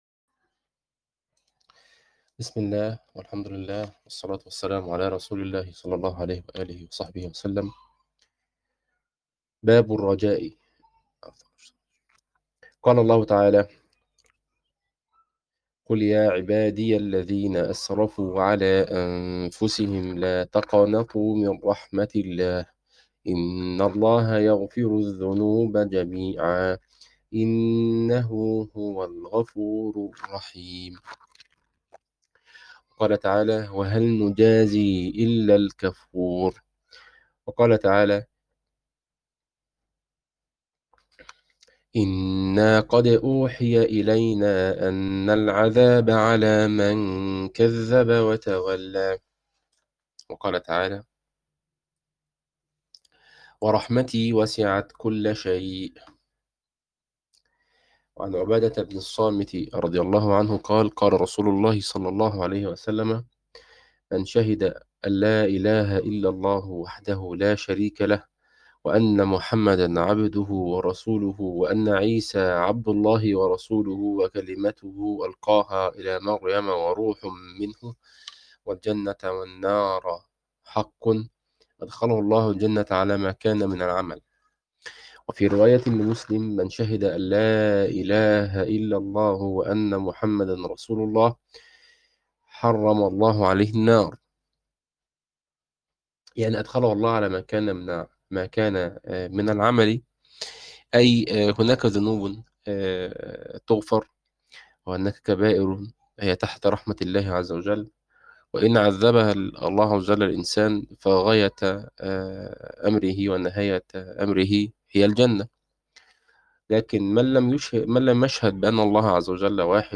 عنوان المادة الدرس 13 | دورة كتاب رياض الصالحين تاريخ التحميل الجمعة 27 يونيو 2025 مـ حجم المادة 47.70 ميجا بايت عدد الزيارات 92 زيارة عدد مرات الحفظ 60 مرة إستماع المادة حفظ المادة اضف تعليقك أرسل لصديق